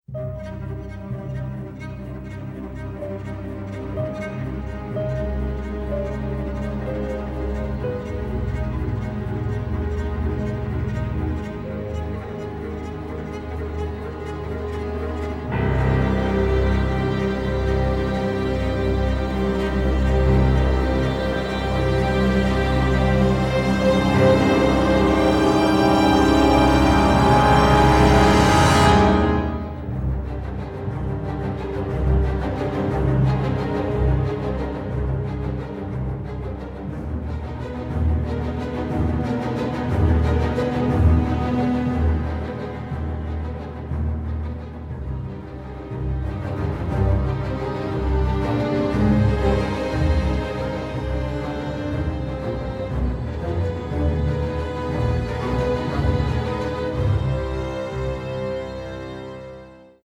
a harrowing, incredibly emotive score
Recorded at Abbey Road Studios